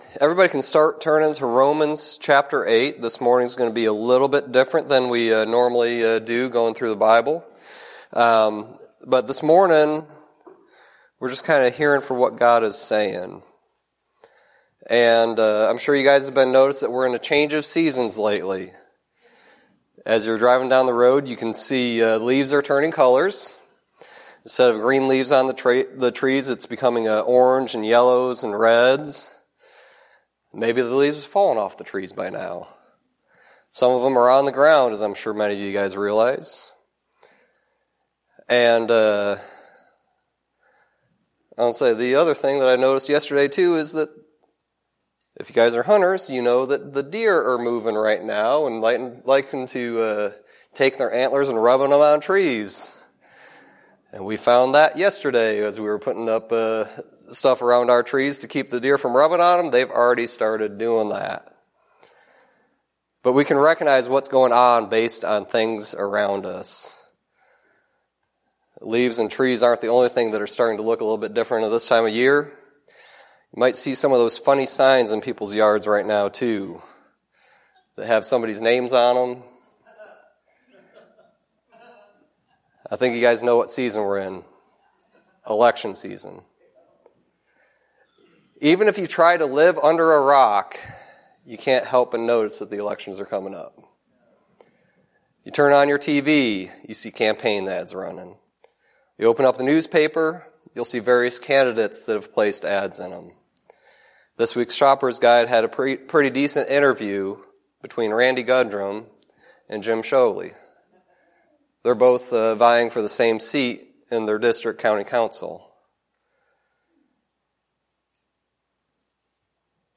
Sermon messages available online.
Service Type: Sunday Teaching